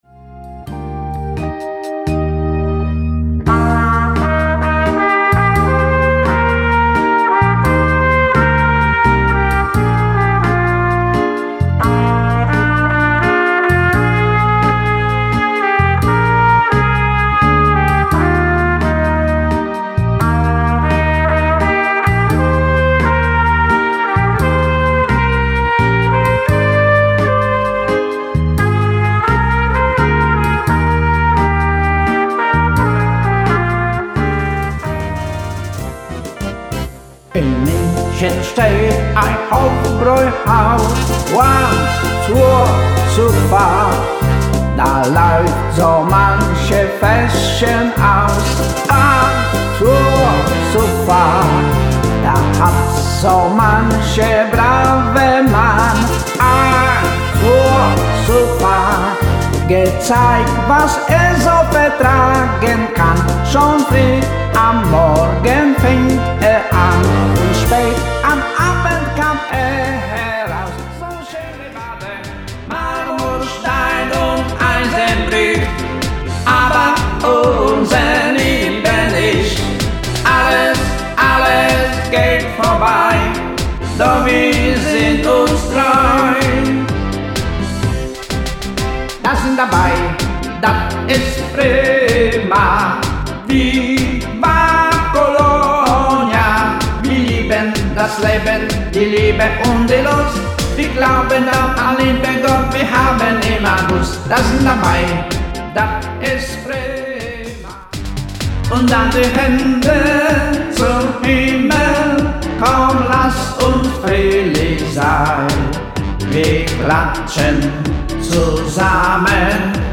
• Alleinunterhalter
• Allround Partyband